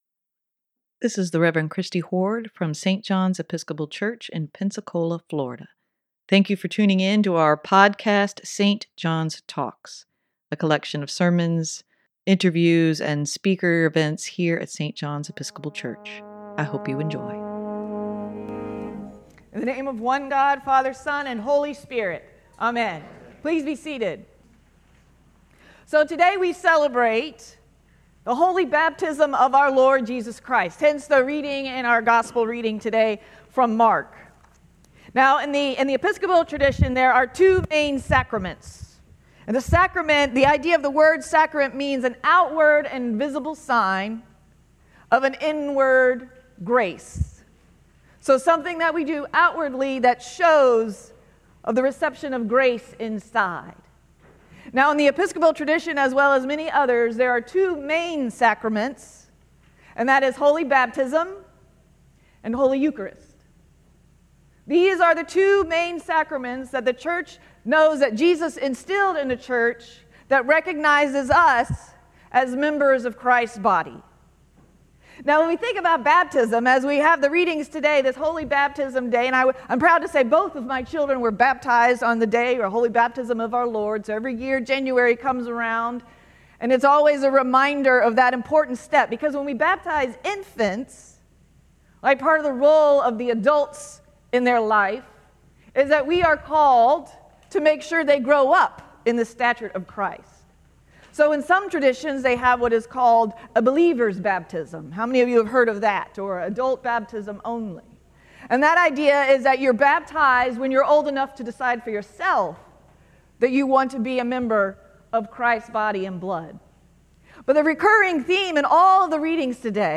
Sermon for Jan. 7, 2024: With you, God is well pleased - St. John's Episcopal Church